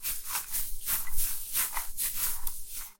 Something moving through tall grass with swishing stems and seed head brushing
tall-grass-movement.mp3